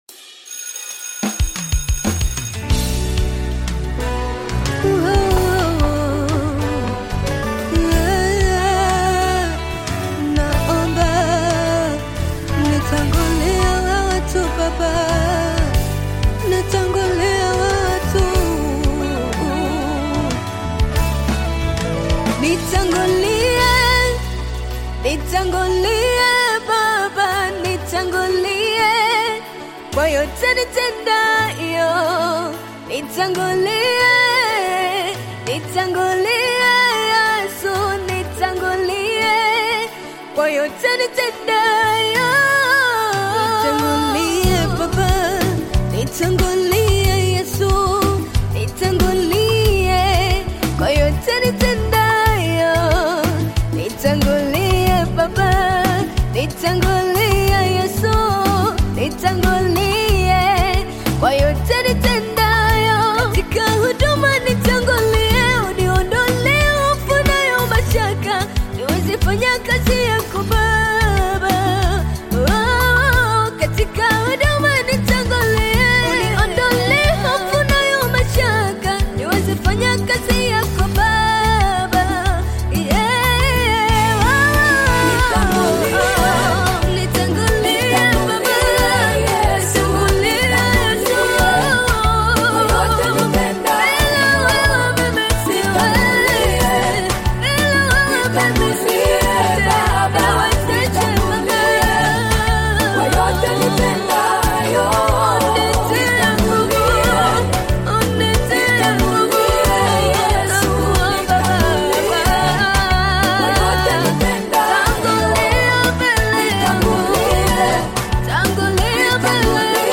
gospel song
African Music